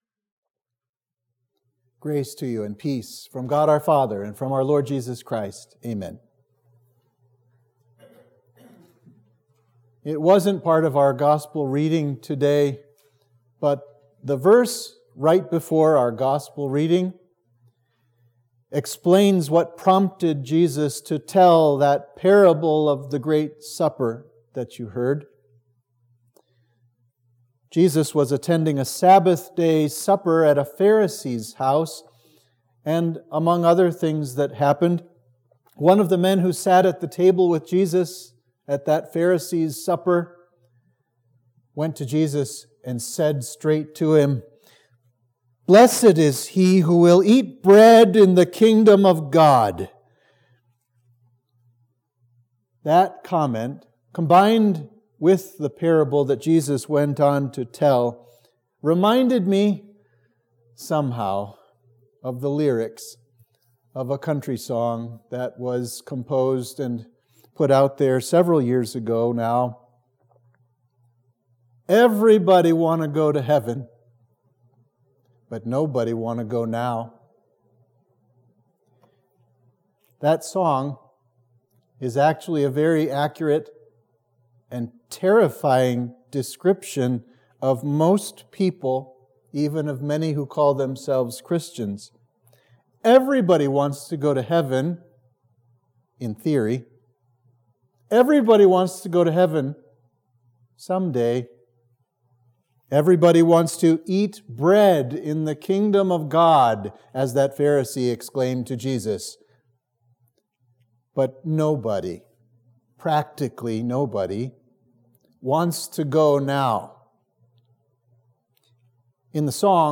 Sermon for Trinity 2